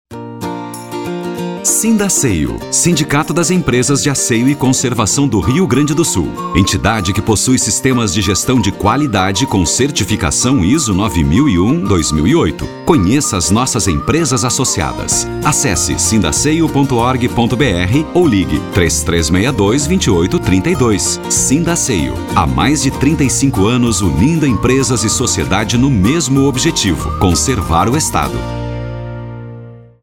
Locutor voz Grave, a disposição para gravação de Spots de Rádio e TV, bem como Esperas Telefônicas e documentários
• spot